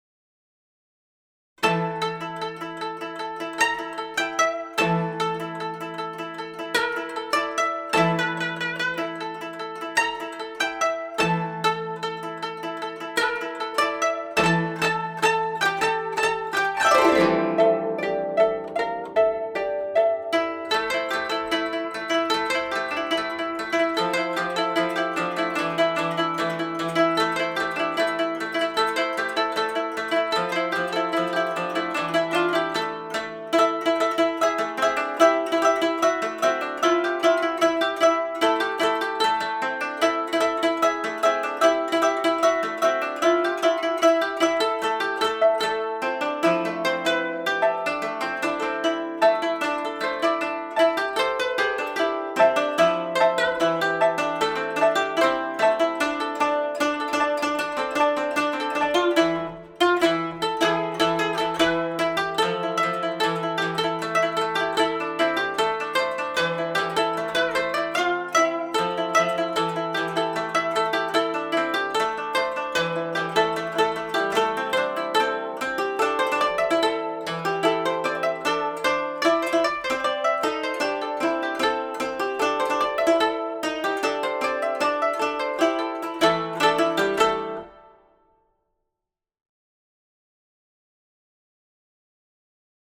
パート編成 箏二重奏
箏二重奏mp3 音源